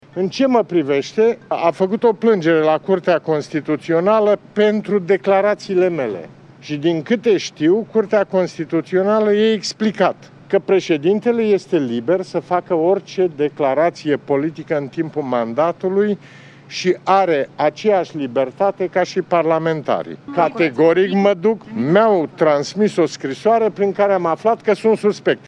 Fostul preşedinte al României, Traian Băsescu, a anunţat, cu puţin timp în urmă, că are calitatea de suspect în dosarul în care este acuzat de şantaj de senatoarea Gabriela Firea şi că va merge, miercuri, la Parchetul General :